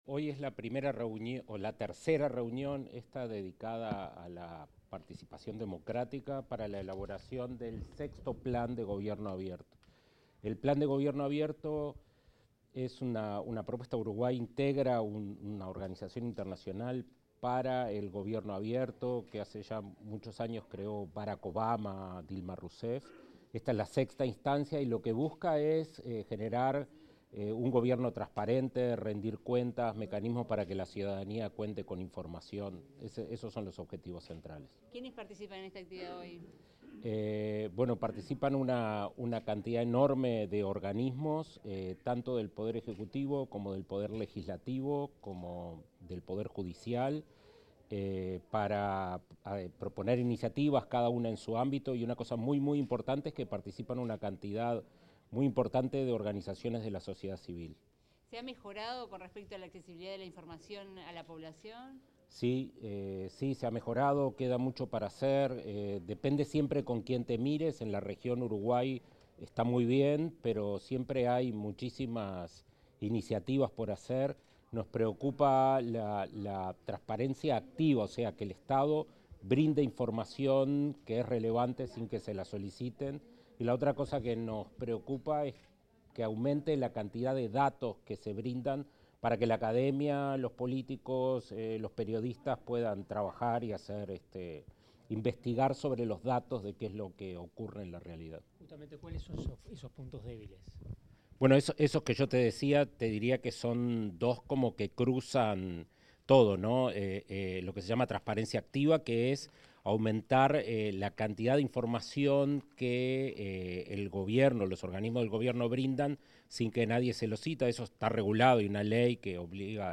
Declaraciones del director ejecutivo de Agesic, Daniel Mordecki
Declaraciones del director ejecutivo de Agesic, Daniel Mordecki 09/06/2025 Compartir Facebook X Copiar enlace WhatsApp LinkedIn El director ejecutivo de la Agencia de Gobierno Electrónico y Sociedad de la Información y el Conocimiento (Agesic), Daniel Mordecki, dialogó con los medios de prensa tras participar en la apertura de la Tercera Mesa de Diálogo de Gobierno Abierto: Democracia, Espacio Cívico y Gobernanza Digital.